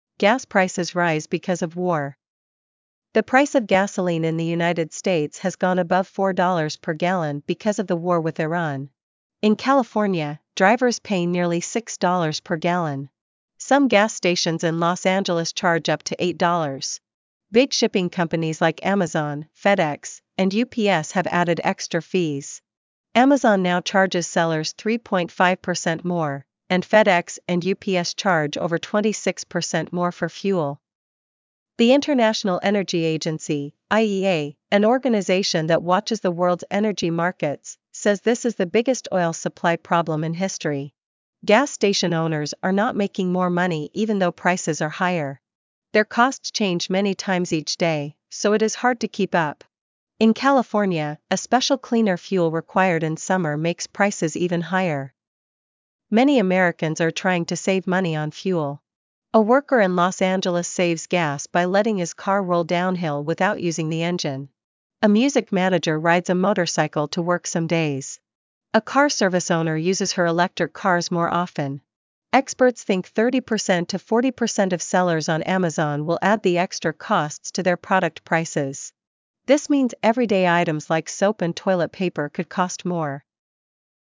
3. 使用 Azure TTS 来生成英文音频 + 字幕
1 、语音不够自然，目前豆包挺自然的，微软好像也有一些人声不错能否使用更拟人的声音；